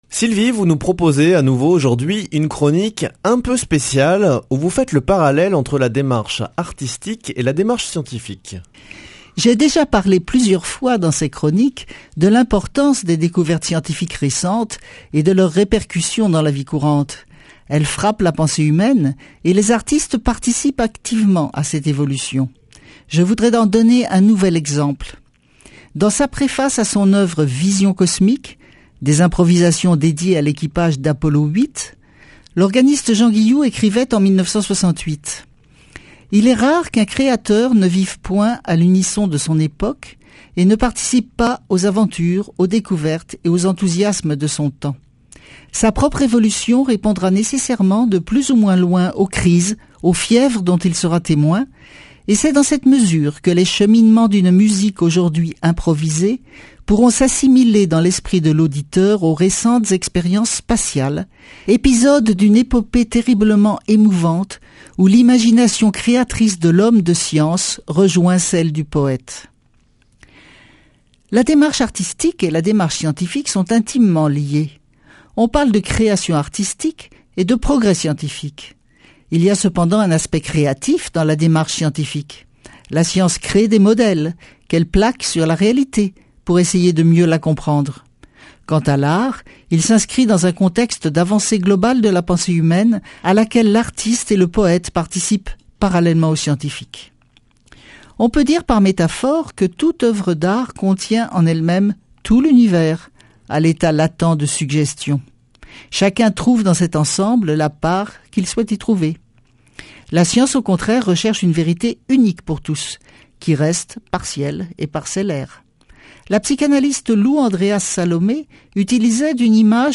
Speech